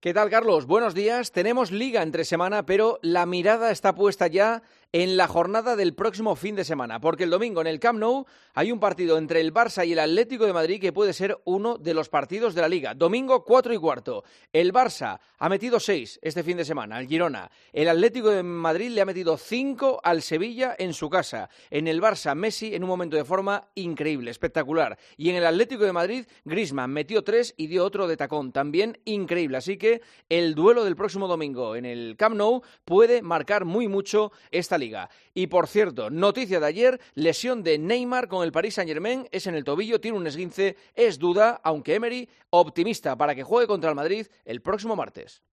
El comentario de Juanma Castaño
El director de 'El Partidazo' de COPE analiza la última jornada de Liga en una semana en la que la competición doméstica no para